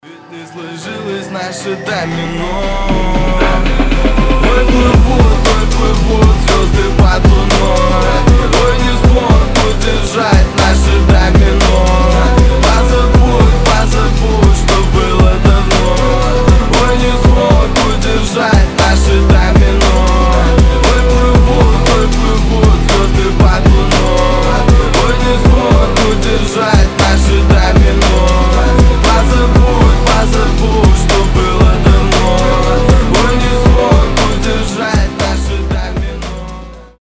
• Качество: 320, Stereo
лирика
грустные
красивый мужской голос